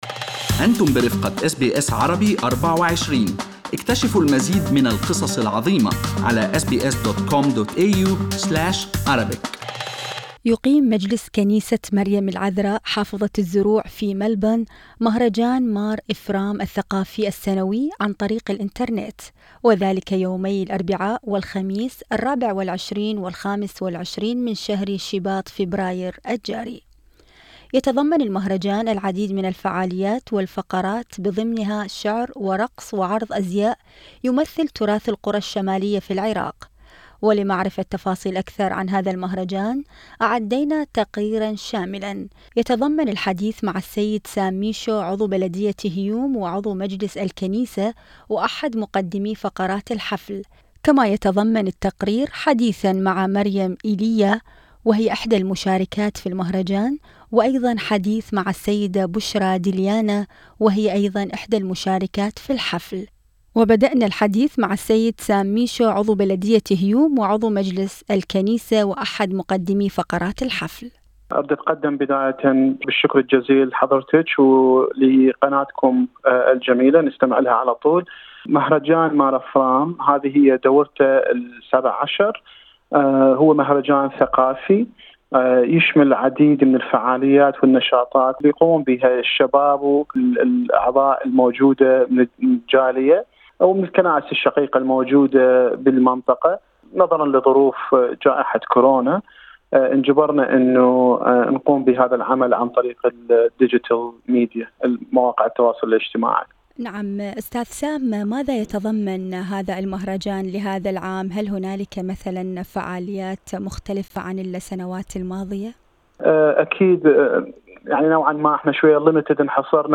ولمعرفة تفاصيل أكثر عن هذا المهرجان، أعينا تقريرا شاملا لمشاركين في المهرجان وبدأنا اللحديث مع السيد سام ميشو عضو بلدية هيوم وعضو مجلس الكنيسة واحد مقدمي فقرات الحفل الذي قال ان مهرجان هذه السنة ينعقد في دورته السابعة عشرة وهو مهرجان ثقافي يشارك فيه مجموعة من الشباب ويتضمن فقرات منها الشعر والرقص والجالغي البغدادي.